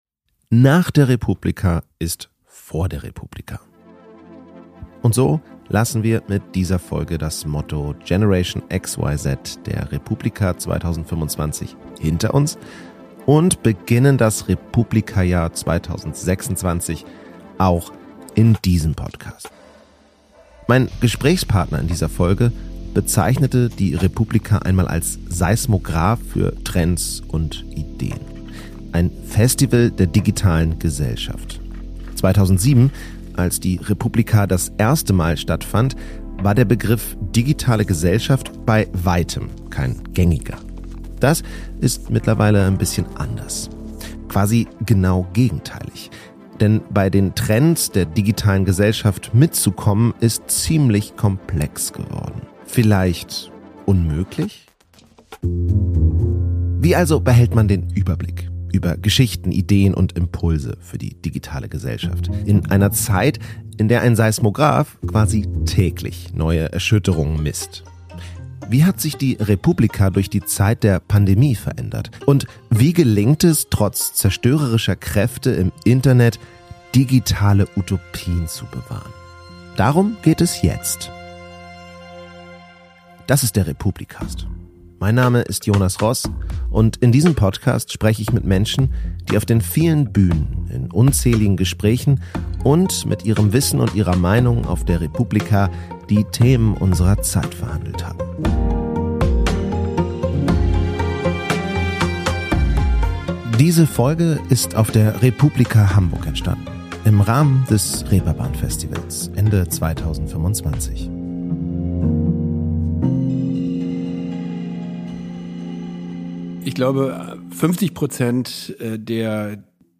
Auf dem Weg zur re:publica 2026 in Berlin melden wir uns mit vier Folgen, die auf der re:publica im Rahmen des Reeperbahn Festivals in Hamburg 2025 entstanden sind.